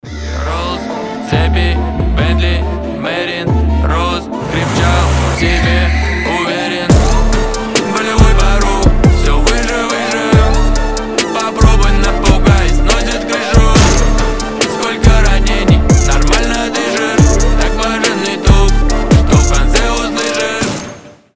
выстрел